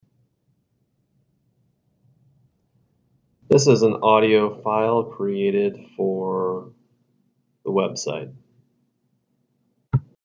The Trojans visit the Timberwolves in Round 1 of the 6A Football Playoffs. LIVE AUDIO 7:00 pm.